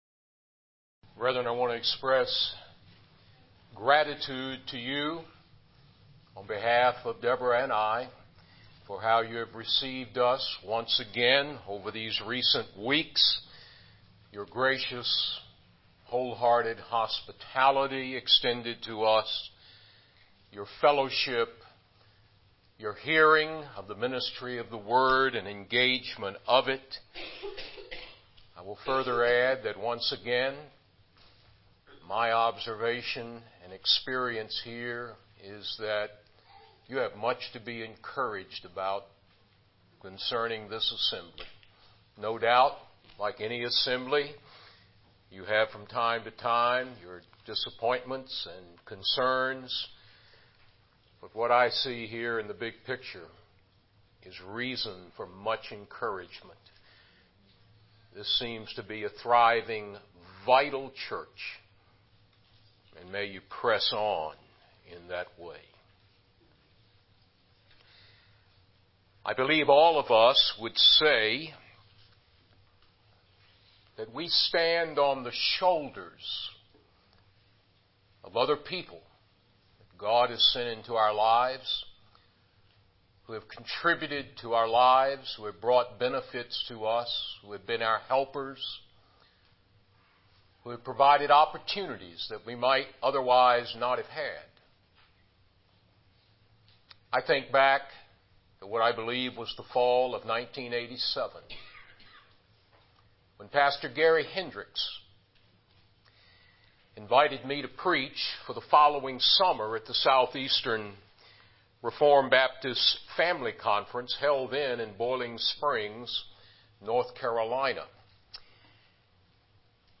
Passage: Colossians 3:19b Service Type: Evening Worship